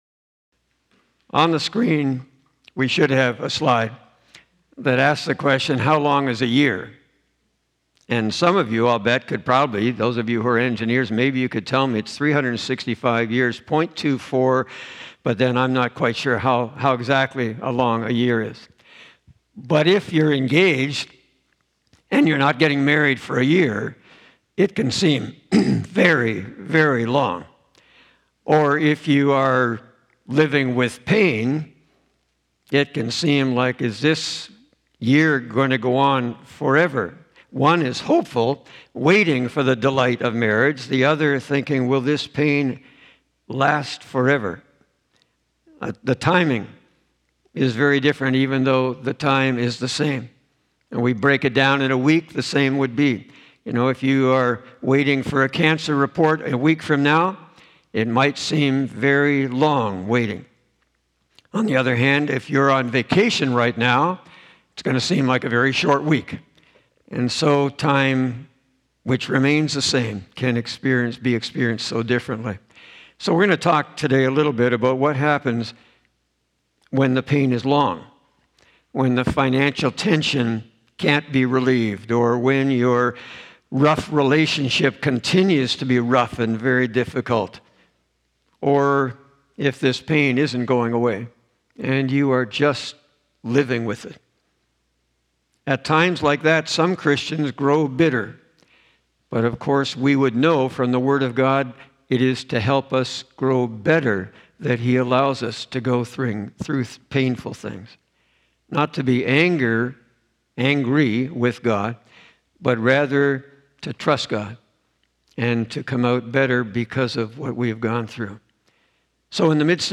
A sermon on Psalm 13.